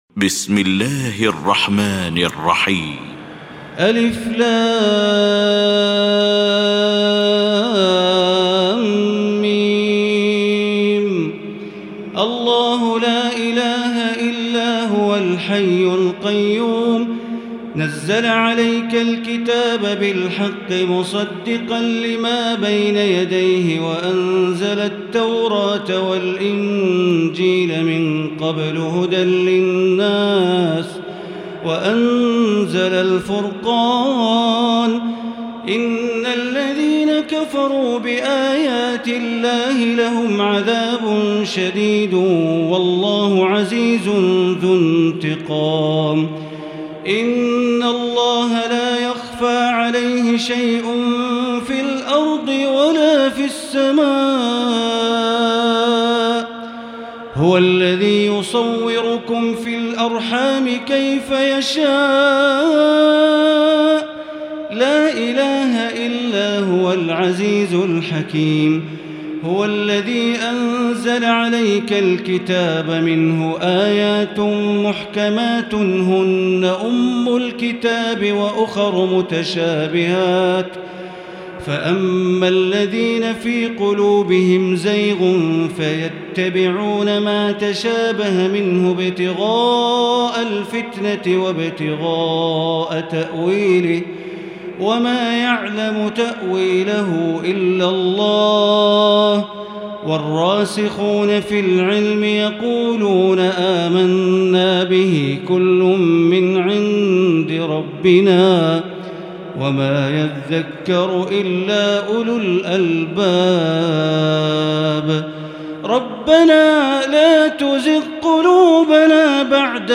المكان: المسجد الحرام الشيخ: معالي الشيخ أ.د. بندر بليلة معالي الشيخ أ.د. بندر بليلة سعود الشريم معالي الشيخ أ.د. عبدالرحمن بن عبدالعزيز السديس فضيلة الشيخ ياسر الدوسري آل عمران